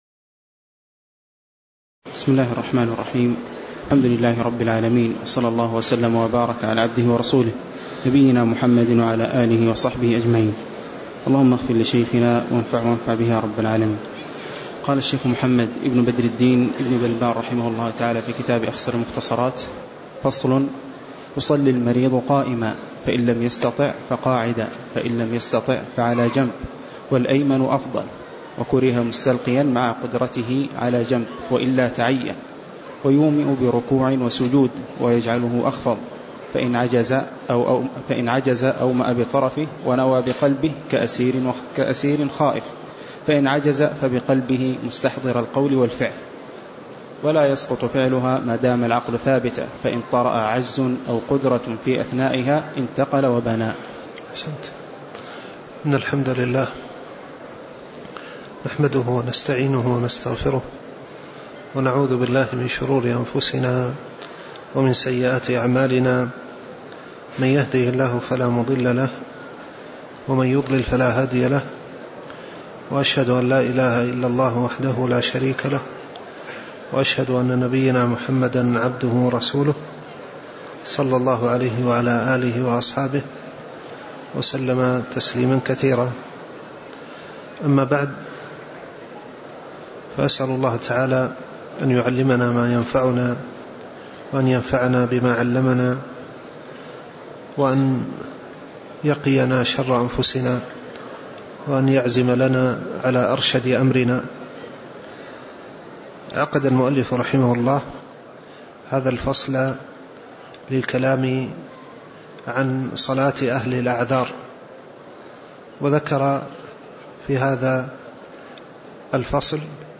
تاريخ النشر ١٠ رجب ١٤٣٩ هـ المكان: المسجد النبوي الشيخ